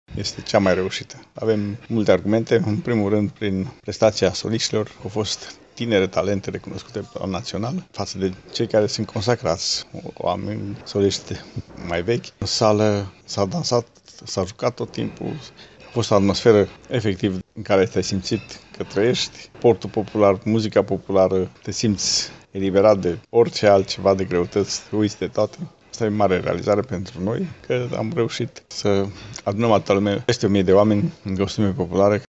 Balul Însuraților a ajuns la cea de-a IX-a ediție, iar în acest an s-a desfășurat în 6 și 7 februarie la Reghin.
Evenimentul a fost organizat de familia deputatului Vasile Gliga, care a caracterizat această ediție ca pe una dintre cele mai reușite de până acum.